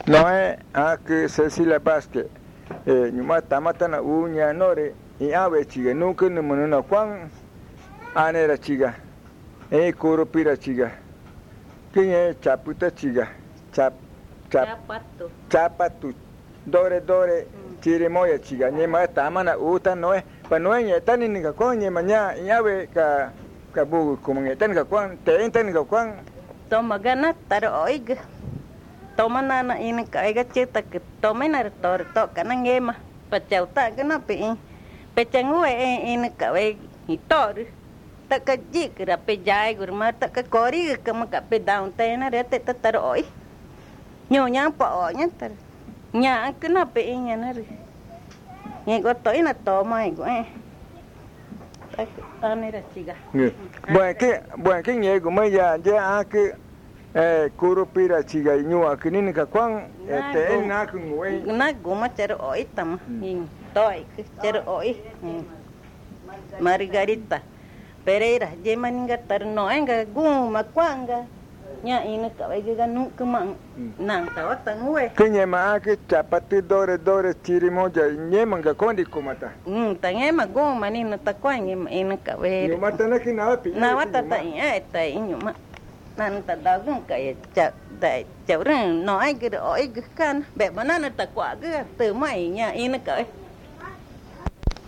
San Juan del Socó, río Loretoyacu, Amazonas (Colombia)